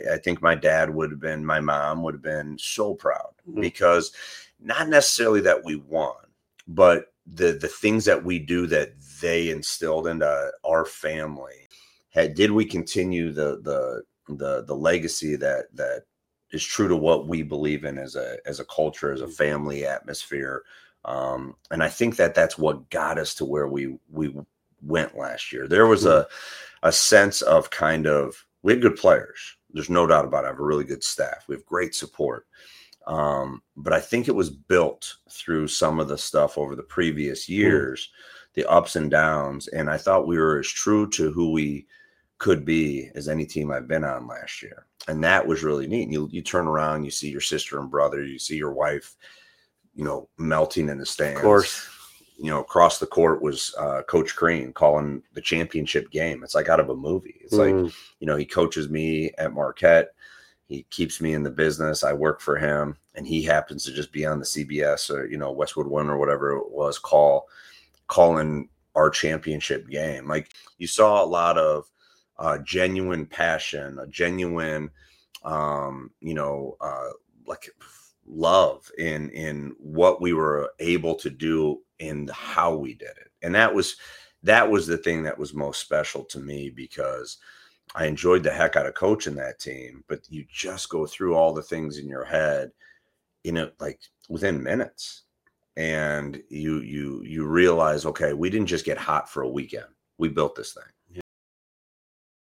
Part One – August Conversation